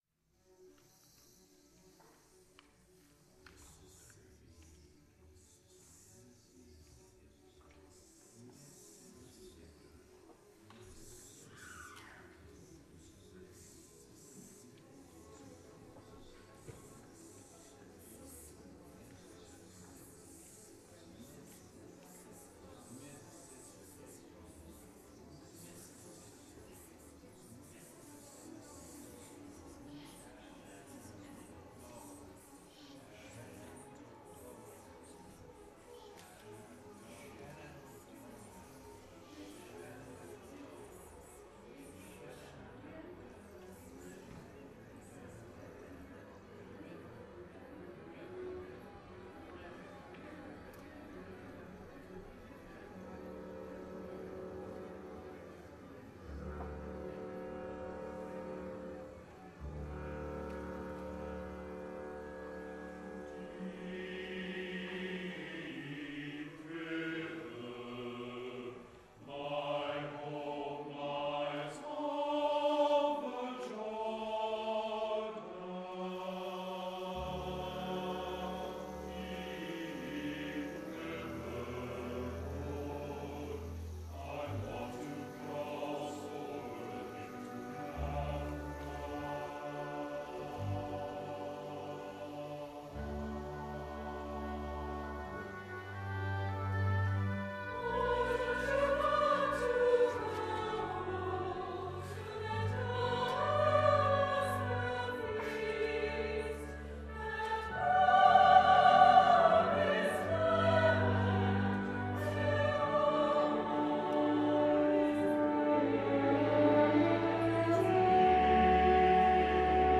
for SATB Chorus and Chamber Orchestra (1996)